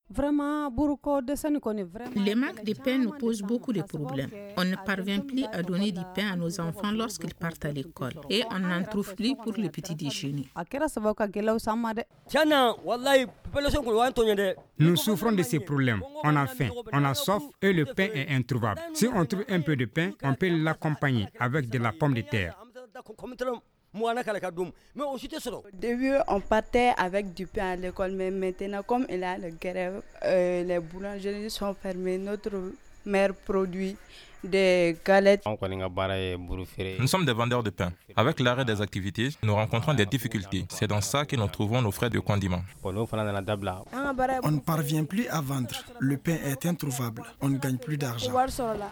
La population sikassoise s’insurge contre le manque de pain dans la ville. Certains habitants de la ville ont accepté de réagir au micro de Studio Tamani.